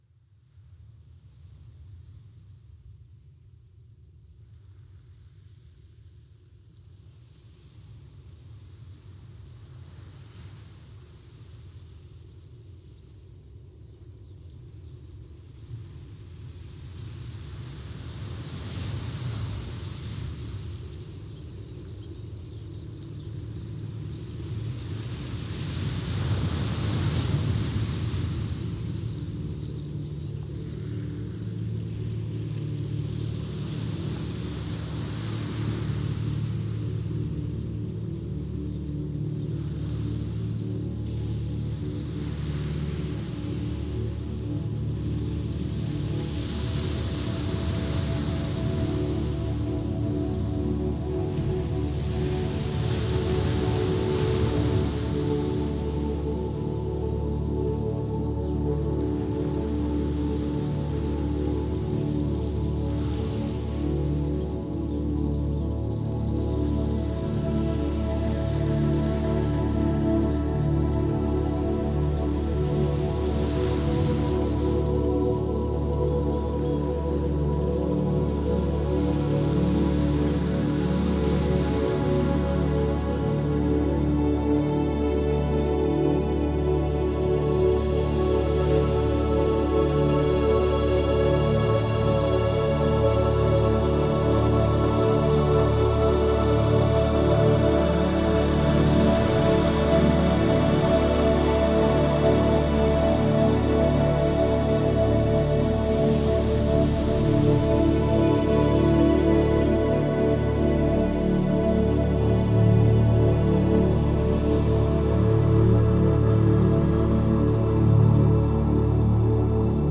música electrónica
estas melodías relajantes y fluidas